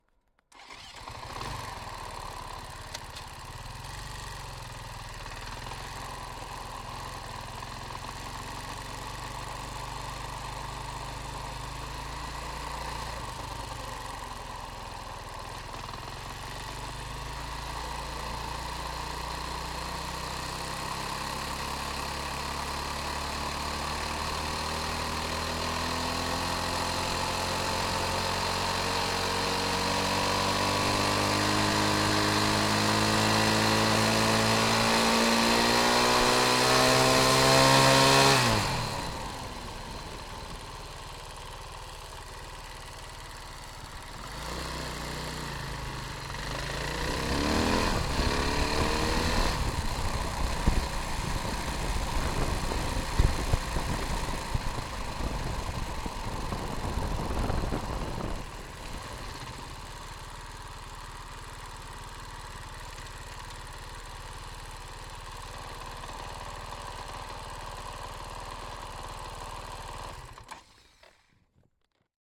Pole Position - Husaberg FE501 500cc 2013 dirtbike
Husaberg_500cc_t3_Onboard_Ramps_Cooler.ogg